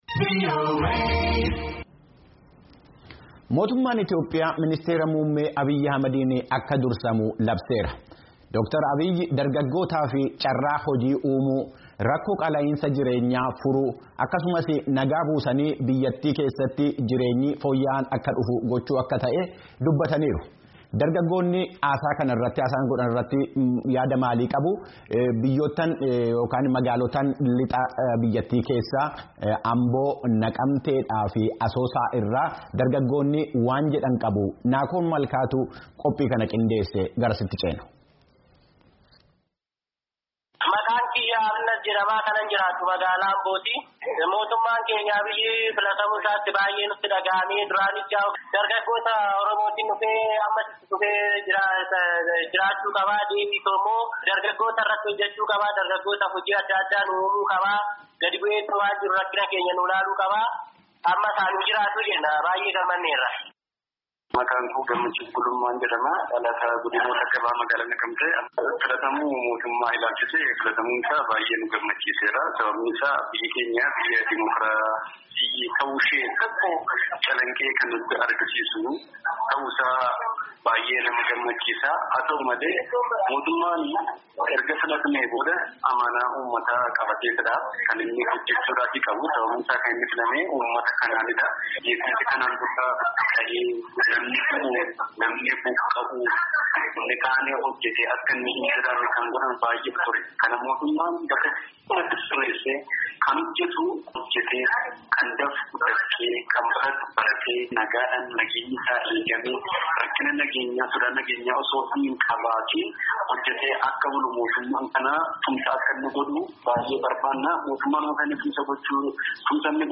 Magaalaa Amboo, Naqamteefi Asoosaarraa yaada dargaggoota kan gabaasaan keenya walitti qabee erge caqasaa.